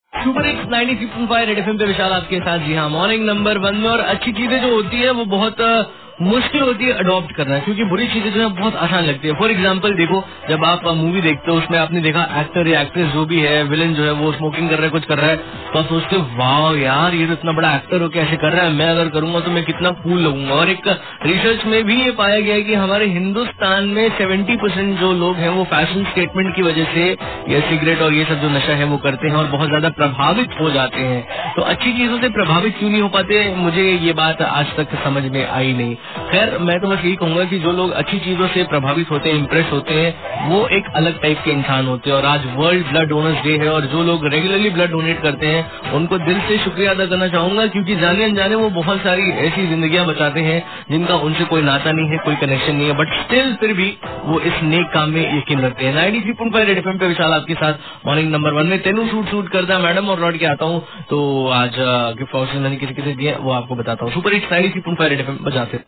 Rj Message For Blood Doner